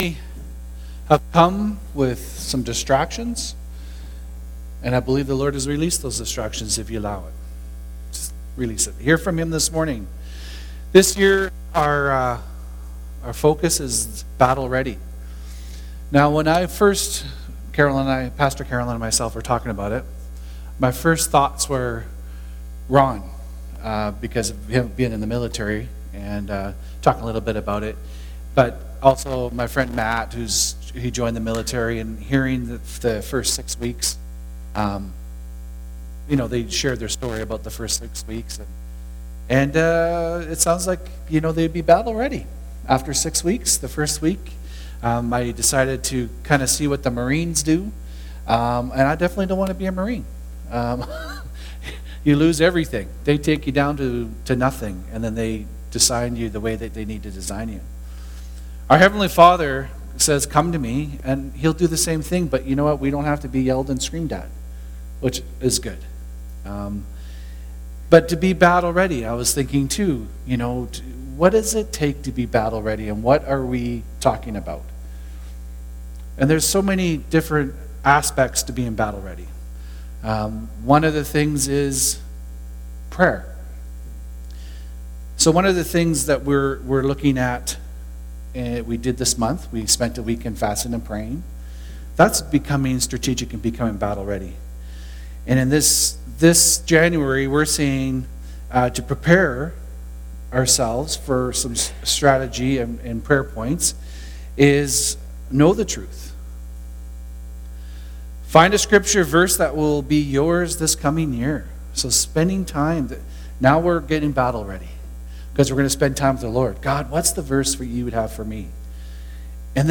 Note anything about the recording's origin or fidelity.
Ephesians 6:10-12 Service Type: Sunday Service « Battle Ready